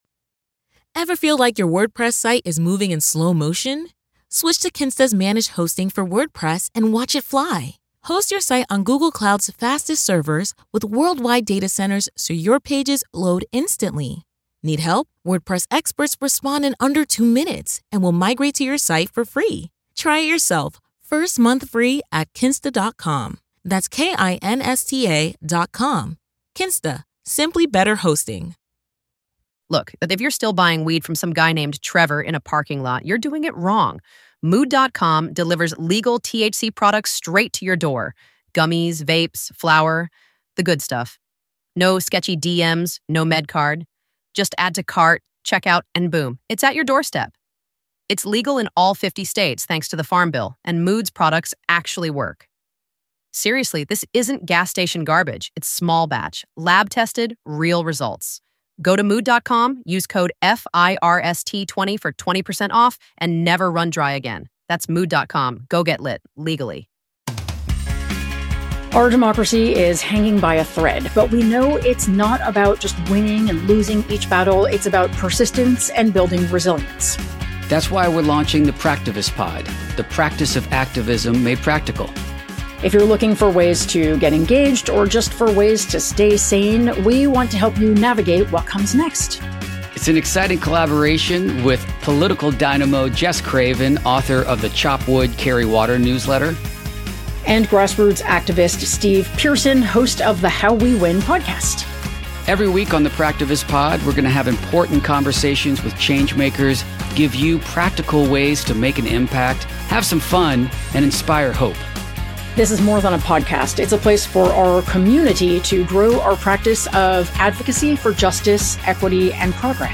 Society & Culture, News, Comedy